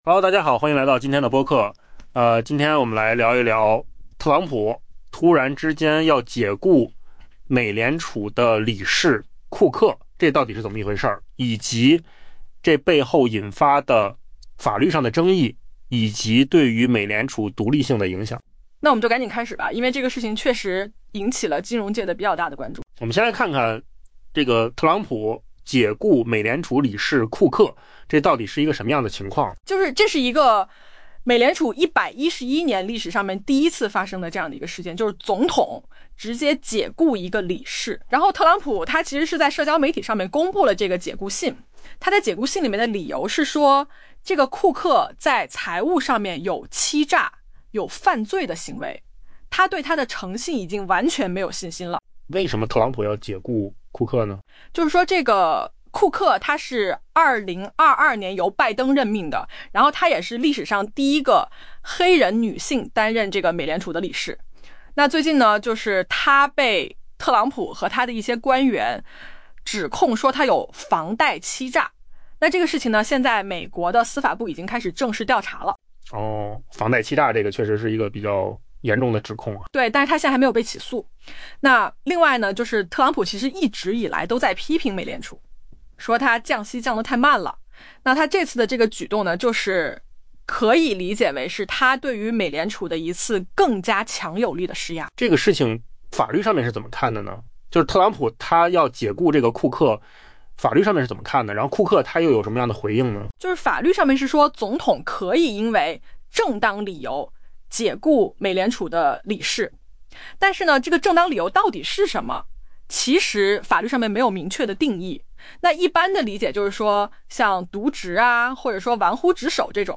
AI播客：换个方式听新闻 下载mp3
音频由口罩空间生成